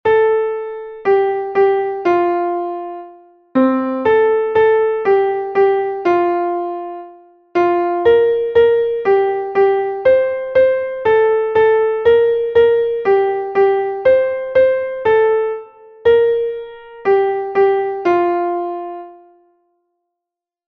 Einstimmige Melodie im Violinschlüssel, F-Dur, 4/4-Takt, mit der 1.
schlaf-kindlein-schlaf_klavier_melodiemeister.mp3